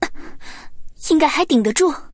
Adv_normalhit.mp3